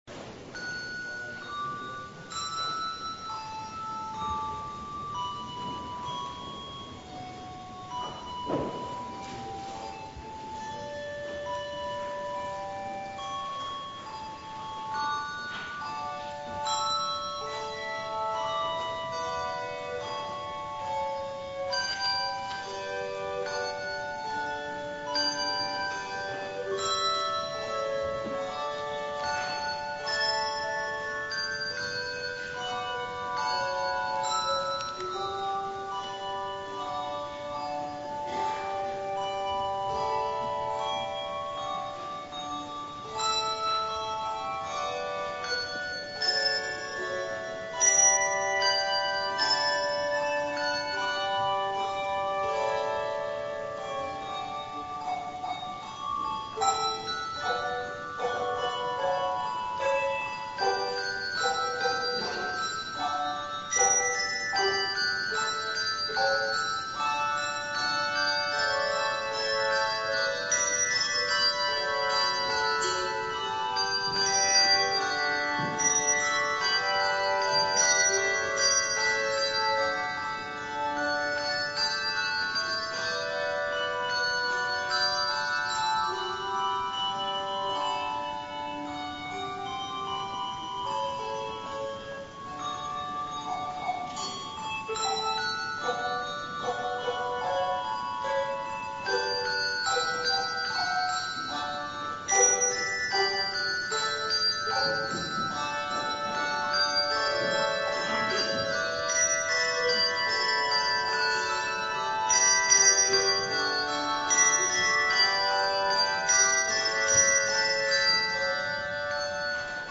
The Second Reformed Bell Choir plays "Canon of Joy" by Pachelbel
Handbell Music